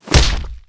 flesh2.wav